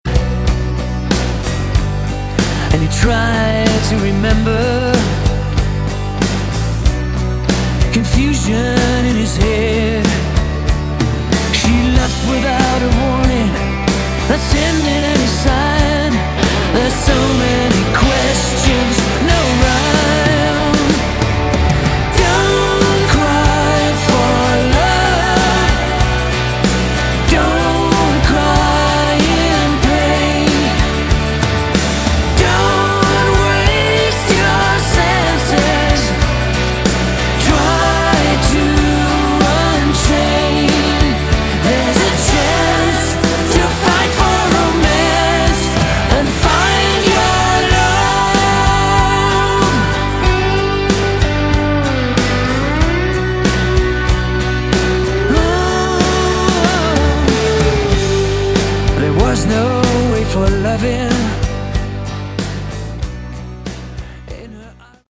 Category: AOR
lead and backing vocals
guitar, backing vocals, keyboards, bass
drums, backing vocals
lapsteel